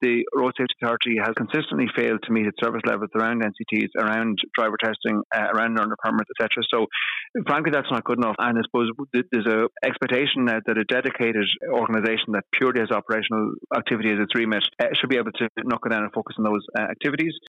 Minister of State for Transport James Lawless hopes it will help address the increasing number of road deaths: